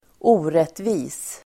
Uttal: [²'o:retvi:s]